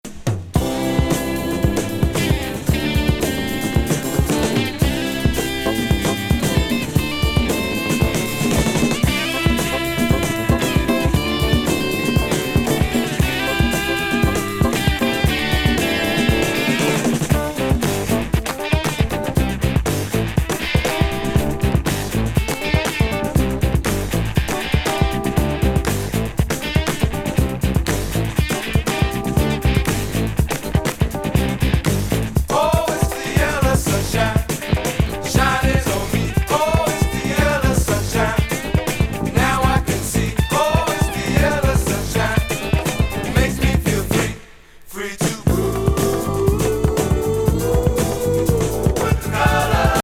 フィリー産ファンク・ロック・グループ
もちろんカッコ良いファジー＆ブギー・ロッキン